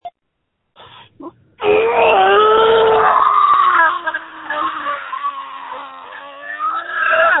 Scream #06129 3:39 UTC 12s 🔗
• When you call, we record you making sounds. Hopefully screaming.